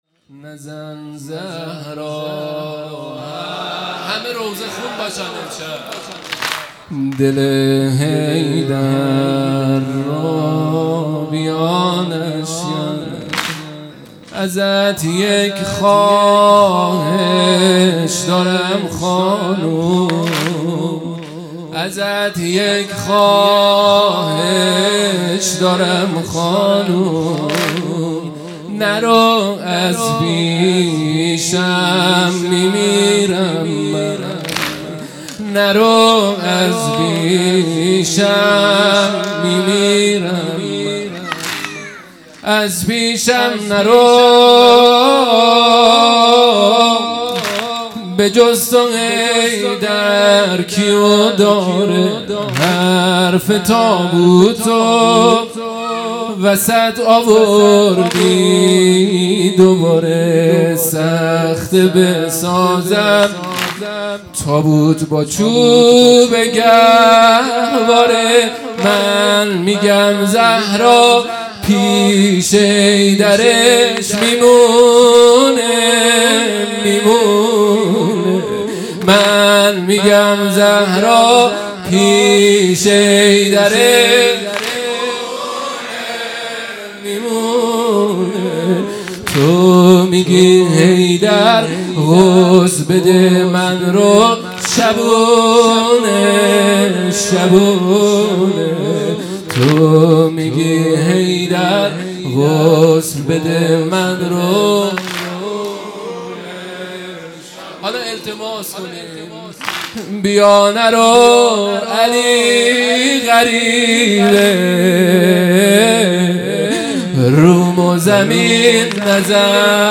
0 0 واحد | نزن زهرا حرفی از رفتن مداح
فاطمیه دوم_شب هفتم